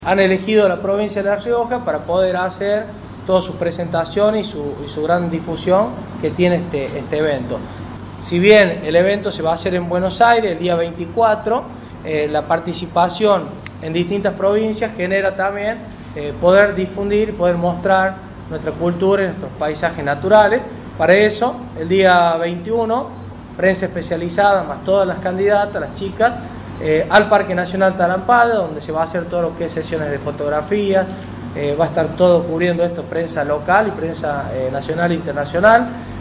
El Gobierno de la Provincia anunció la presentación del desfile “Miss Global Teen” que se realizará el próximo jueves 22 a partir de las 21 en las escalinatas de Casa de Gobierno. Así lo informó el secretario de Turismo, Álvaro del Pino, en conferencia de prensa, este martes en el Salón Blanco de la sede gubernamental.
Alvaro del Pino, secretario de Turismo de la provincia
alvaro-del-pino-secretario-de-turismo-de-la-provincia1.mp3